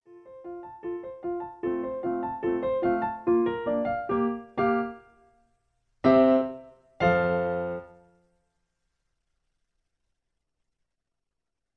In C, F. Piano Accompaniment